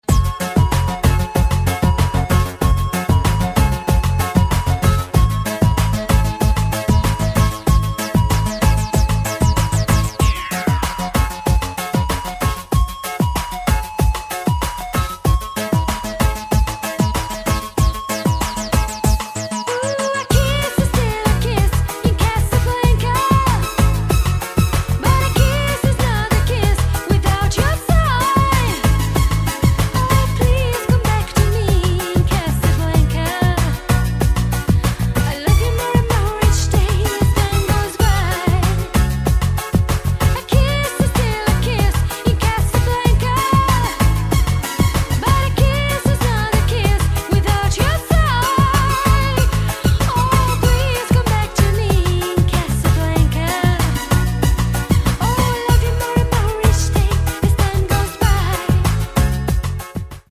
• Качество: 128, Stereo
поп
диско
dance
спокойные
красивый женский голос
80-ые
вокал
дискотека 80-ых